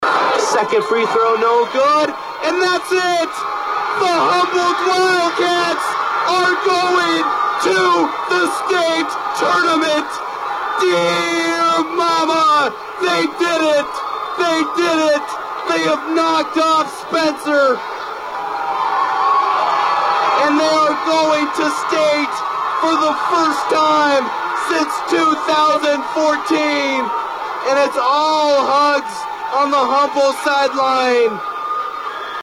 Here is how the final sequence sounded on KHBT.